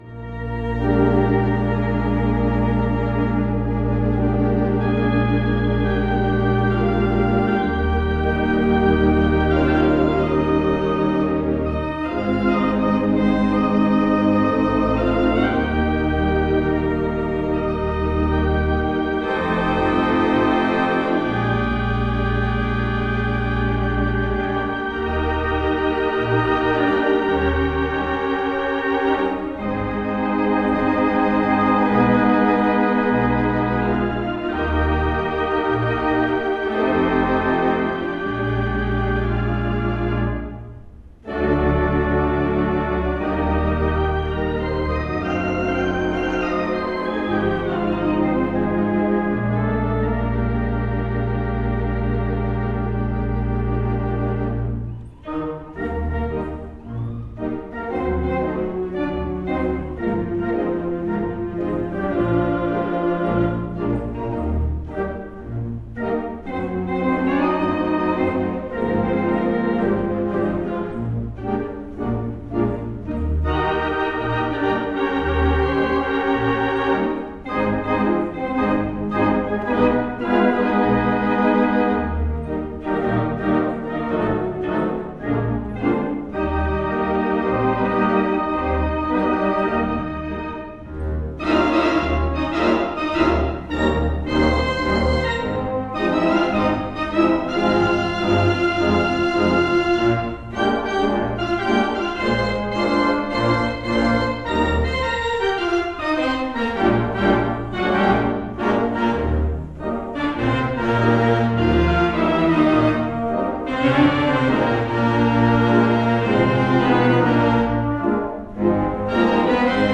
2/11 Mighty WurliTzer Theatre Pipe Organ
Lafayette Theatre, Suffurn, New York